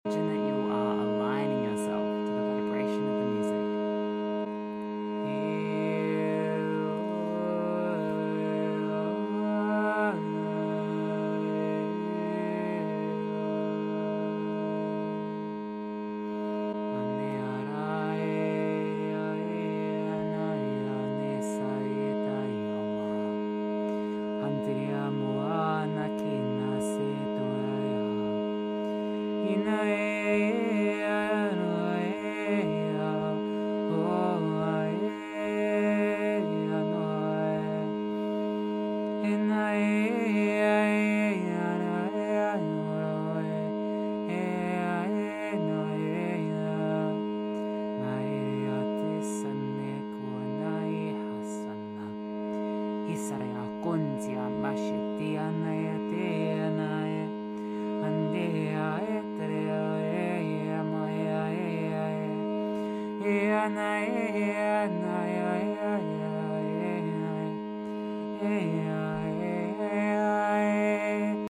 Galactic sound frequency and sound sound effects free download